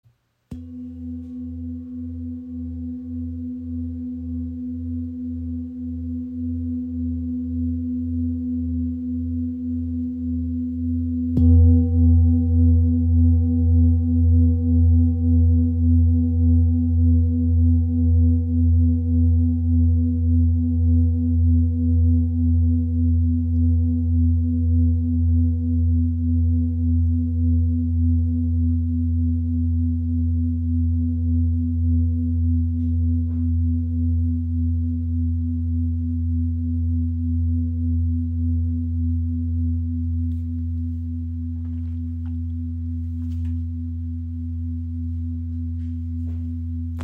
Klangschale mit Yin Yang Baum | ø 30 cm | Ton ~ E2 | Sonnenton (256 Hz)
• Icon Inklusive passendem rotem Filzschlägel. Gewicht 2748 g
• Icon Grundton E2 bei 248 Hz – nahe am kraftvollen Sonnenton
Ihr obertonreicher, warmer Klang lädt Dich ein, zur Ruhe zu kommen und Dich mit Deiner Mitte zu verbinden.